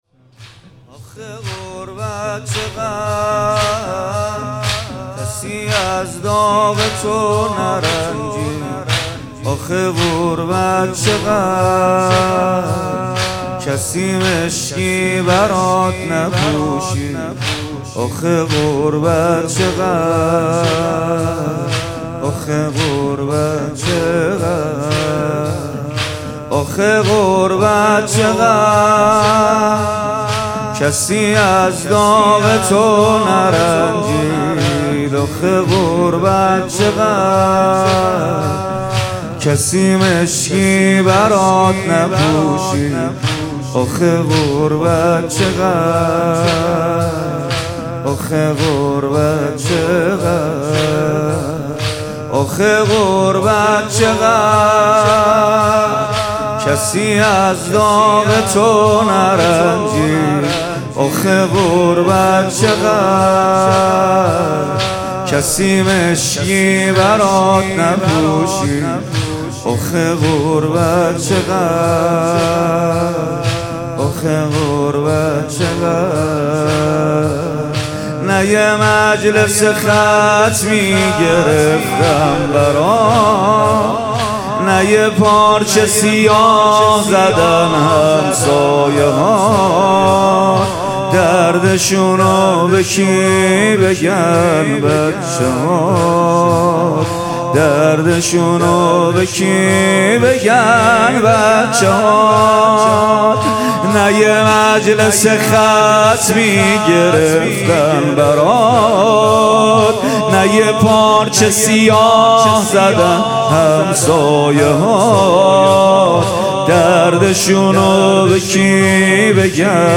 مداحی ایام فاطمیه